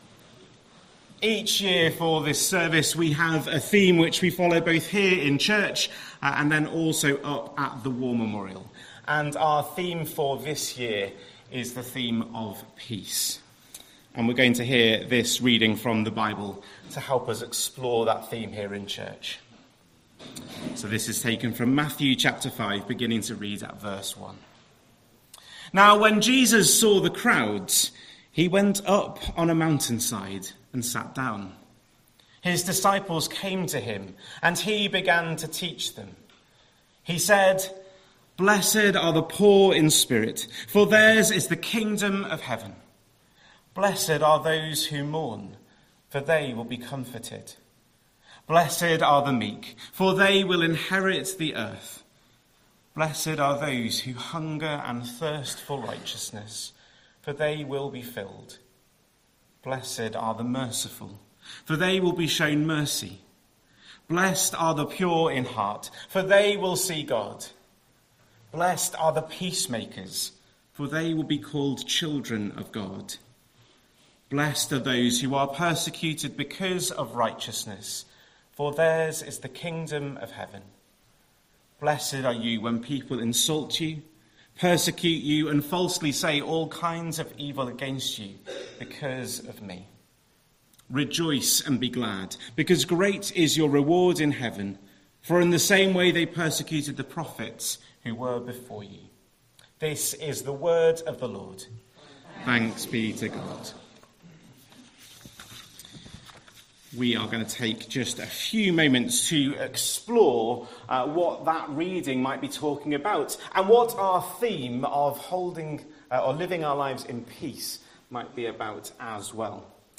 9th November 2025 Sunday Reading and Talk - St Luke's